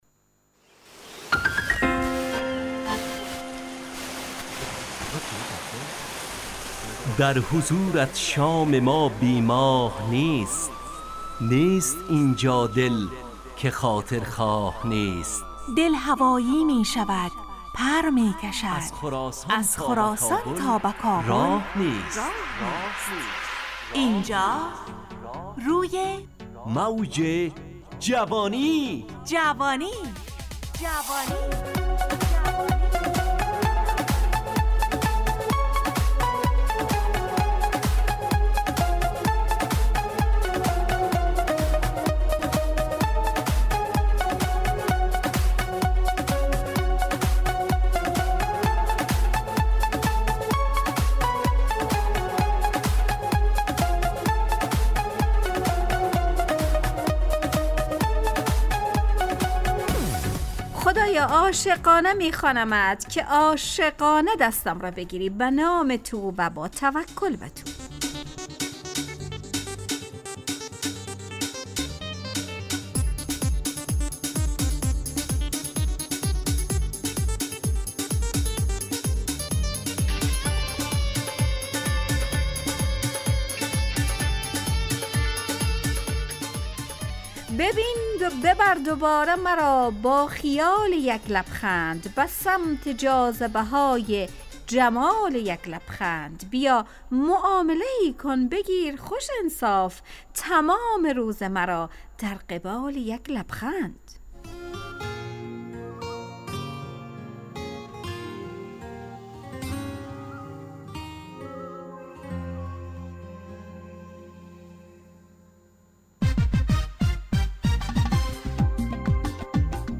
برنامه شادو عصرانه رادیودری.
همراه با ترانه و موسیقی مدت برنامه 70 دقیقه . بحث محوری این هفته (آیینه)